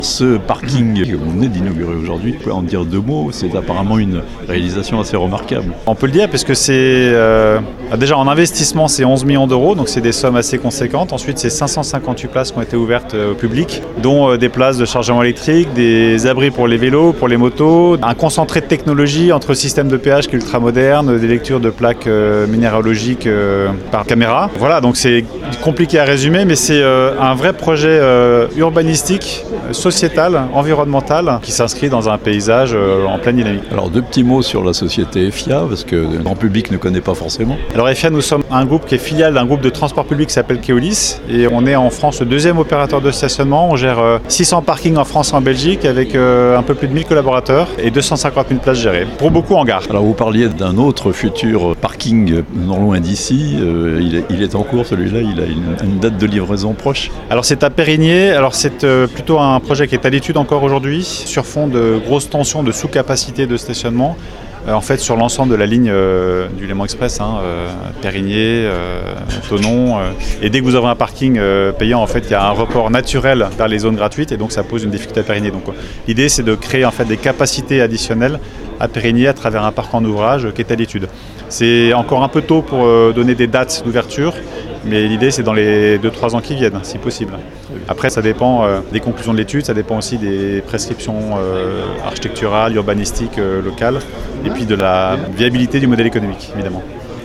Le parking de la gare officiellement inauguré à Thonon (interview)